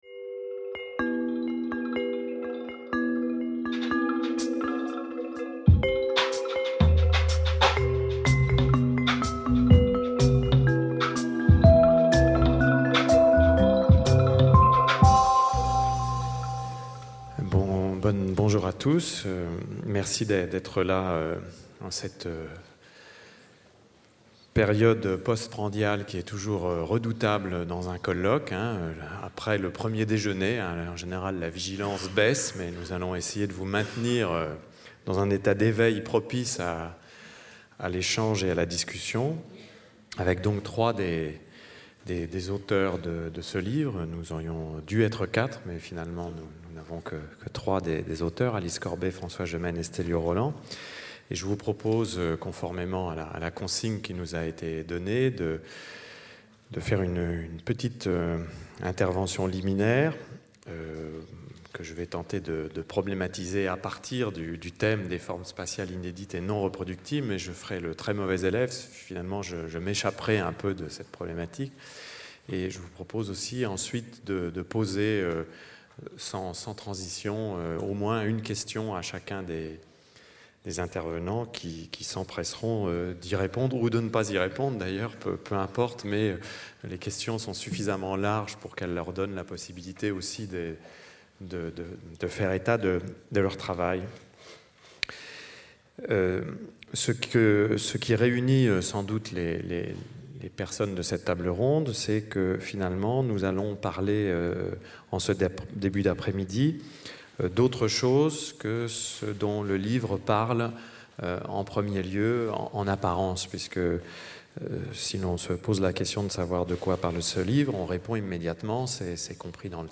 Organisé autour de l’ouvrage collectif Un Monde de Camps, dirigé par Michel Agier et publié aux éditions La Découverte, un débat réunit un riche panel d’intervenants français et internationaux sur les espaces de la mobilité, la diffusion de la forme-camp à l’échelle mondiale et ses effets sociaux, politiques, urbanistiques ou architecturaux.